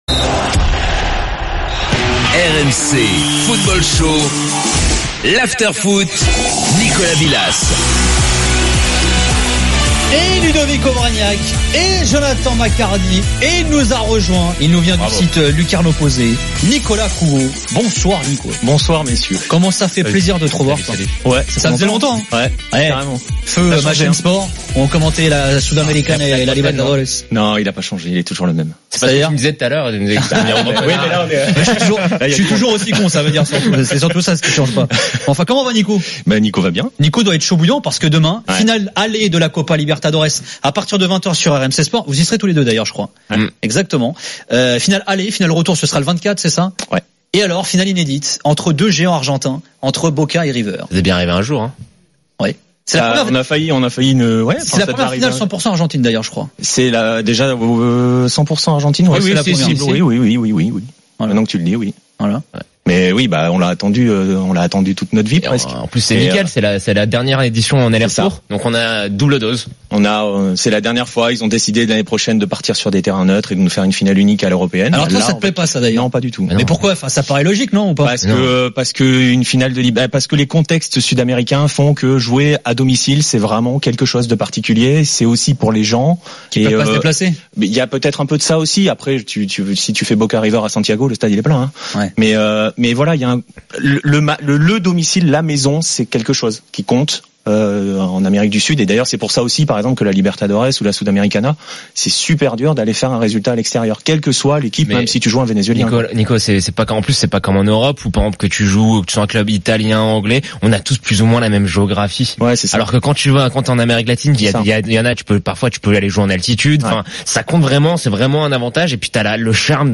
Après le match, avec Gilbert Brisbois, Daniel Riolo et Jérôme Rothen, le micro de RMC est à vous !